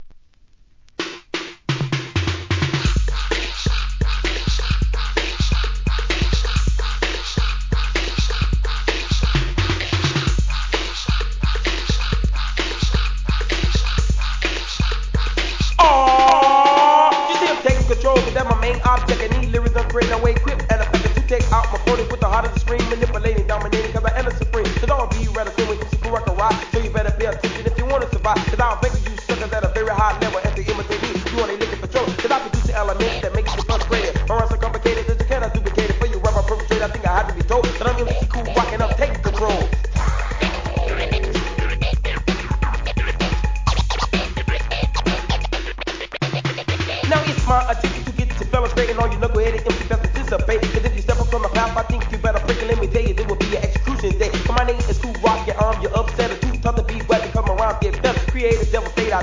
HIP HOP/R&B
高速RAPPIN'にキレキレ・スクラッチが堪能できる